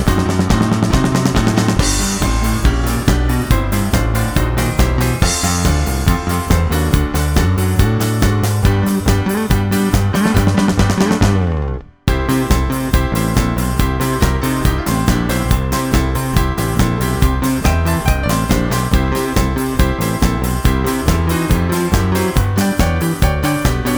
for solo male Rock 'n' Roll 3:54 Buy £1.50